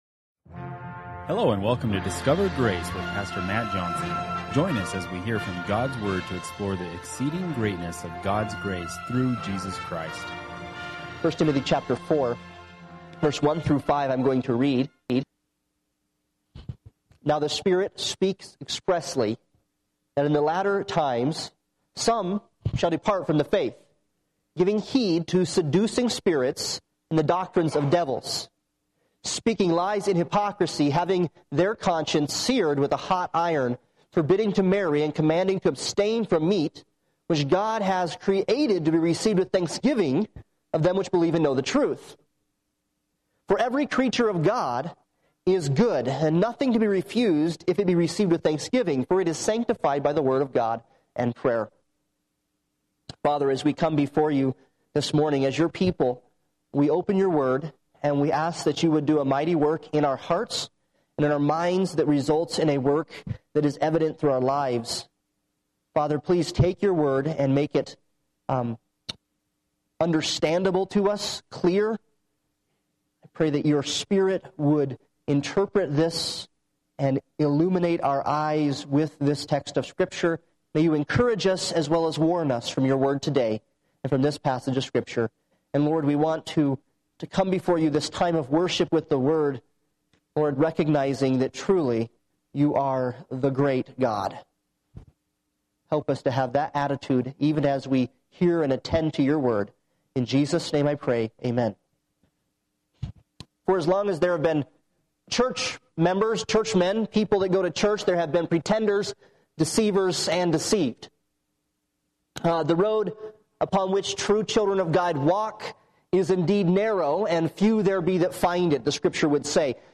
1 Timothy 4;1-2 Service Type: Sunday Morning Worship « The Churchs Hymn